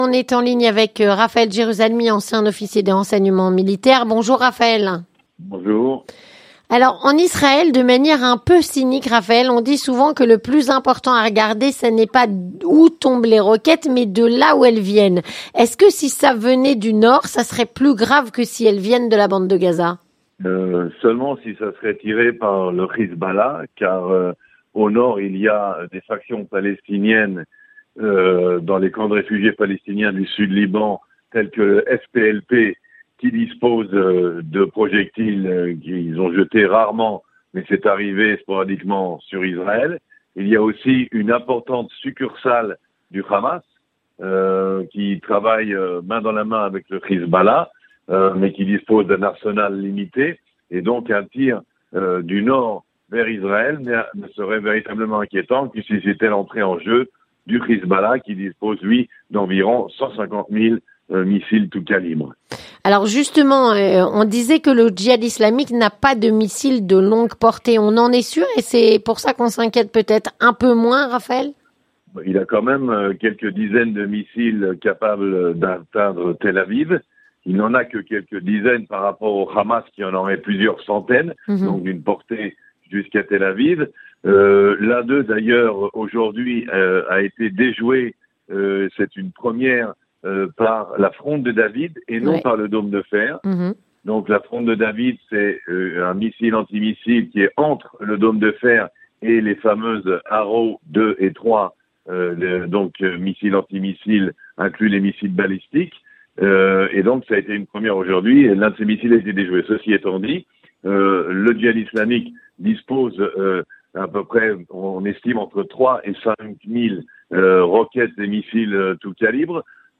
Entretien du 18h - La situation sécuritaire en Israël